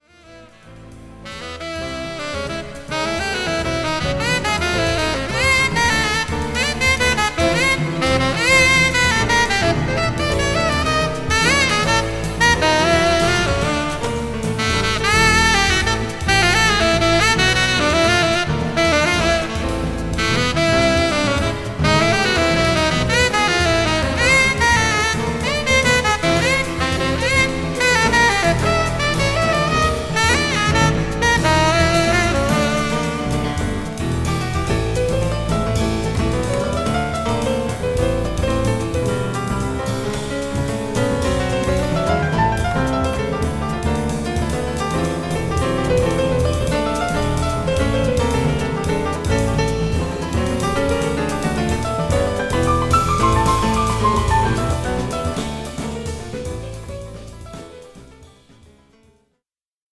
1979年東京でのコンサートを録音した音源を10年後の1989年にリリースしたアルバム。